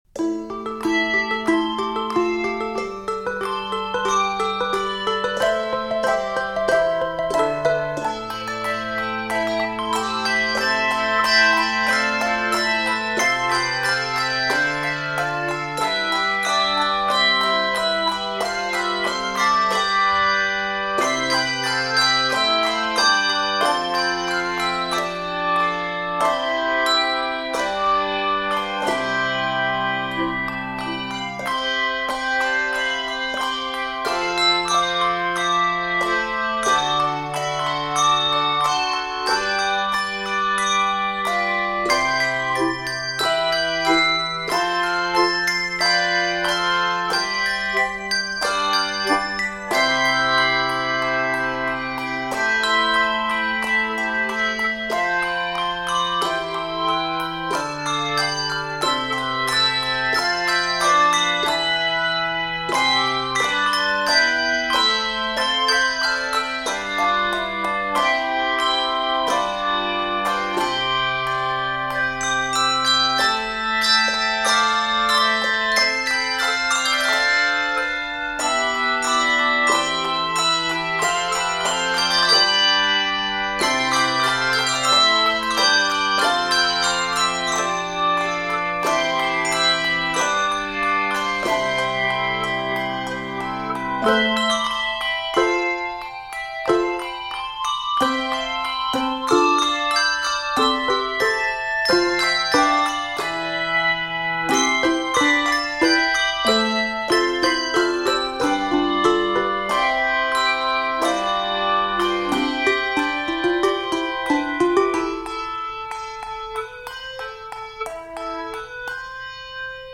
handbells
is an energetic and uplifting original composition